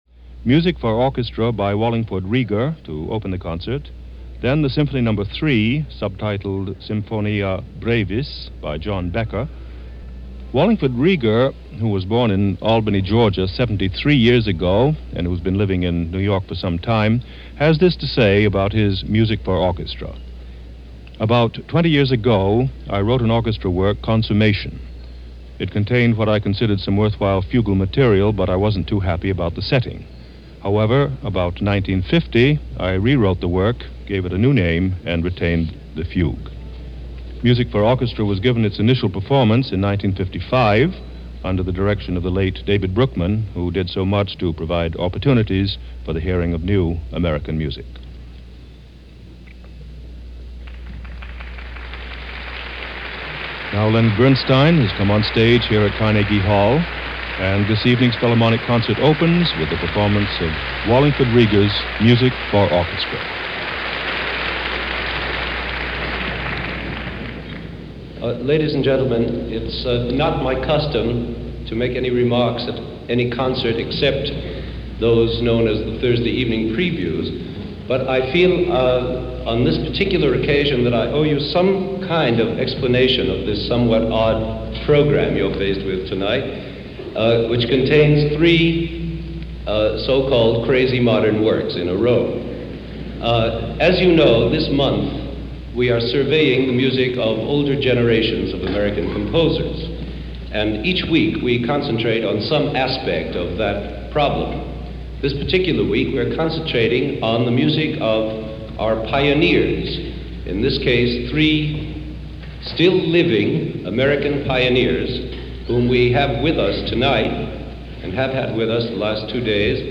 Another rare broadcast concert this weekend.